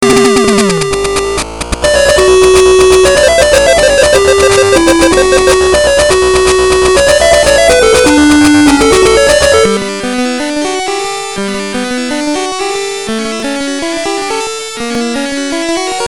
extremely short extract